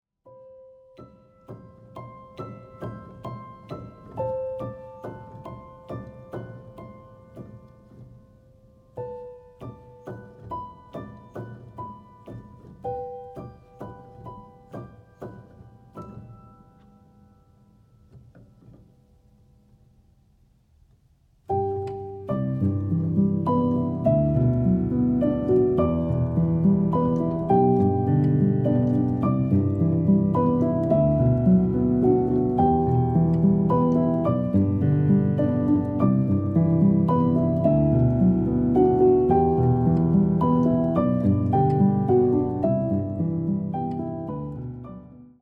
延々と続く桜並木を歩む夢を見るようなアルバムです。